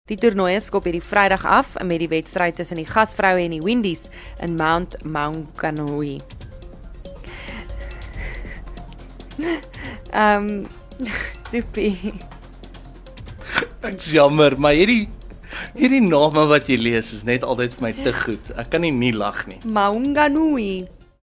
kan sy lag nie hou nie